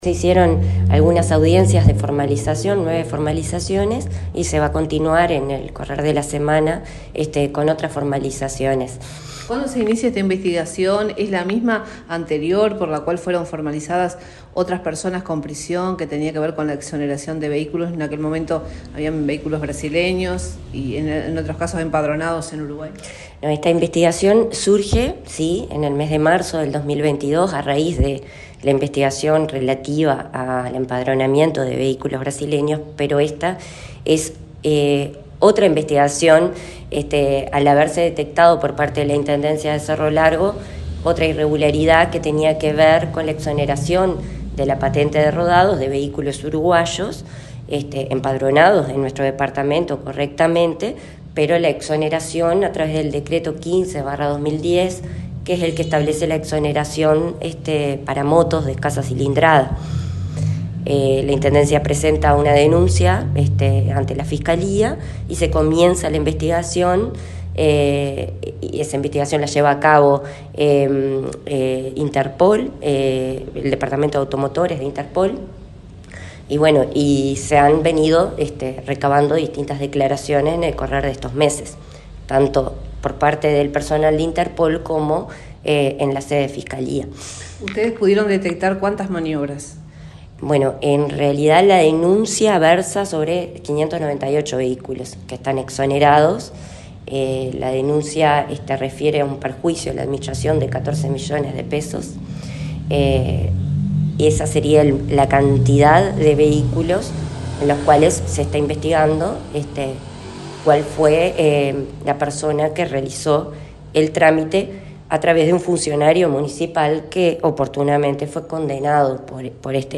Entrevista a la fiscal de 2º Turno, María Eugenia Caltieri: